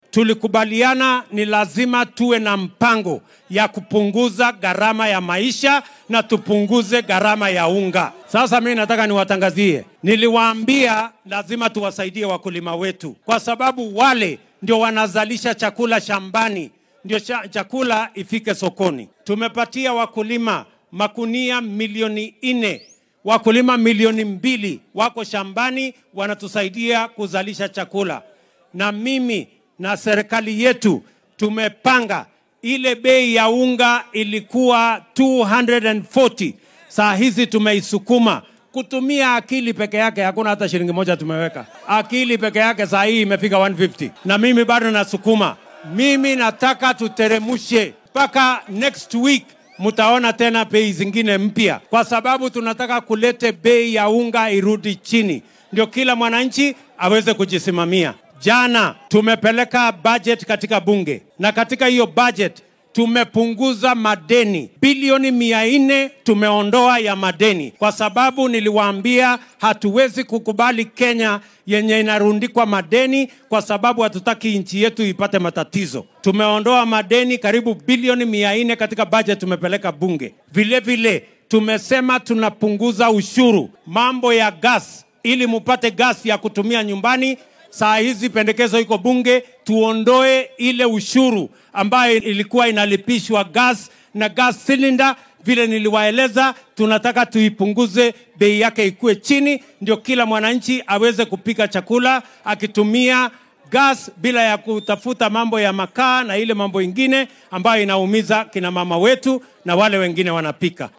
Waxaa uu dadweynaha kula hadlay degmada Ongata Rongai ee dowlad deegaankaasi.